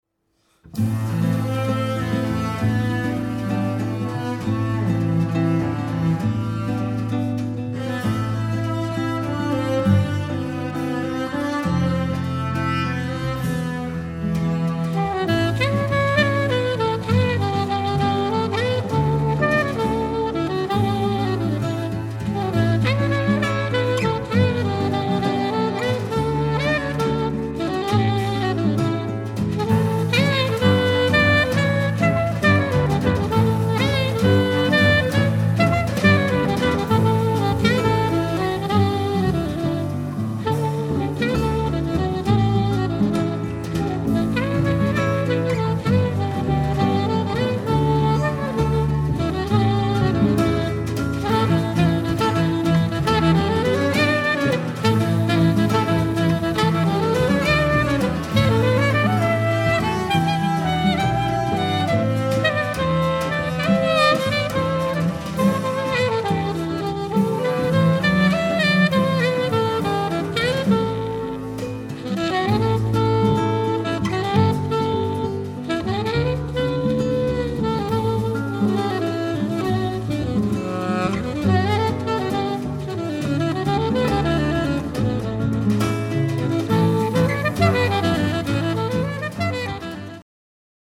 sax contralto/clarinetto
violoncello
chitarre
contrabbasso
batteria